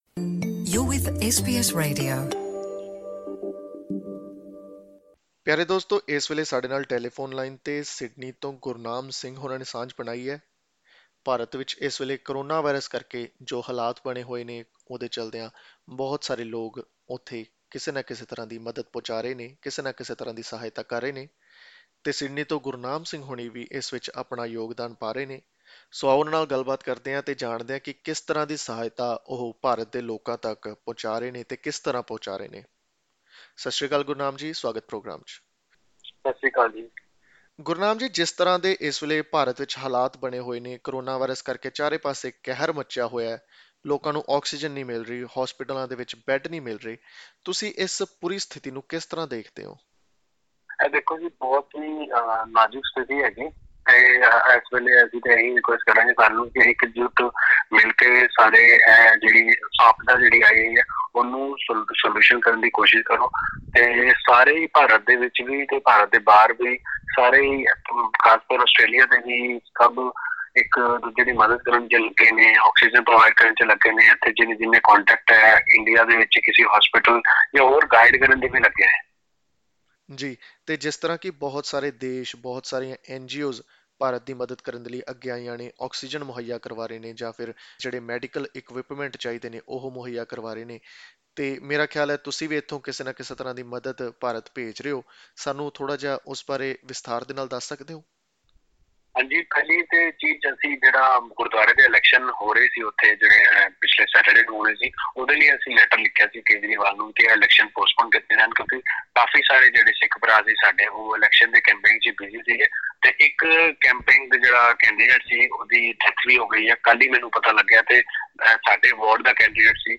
ਇੰਟਰਵਿਊ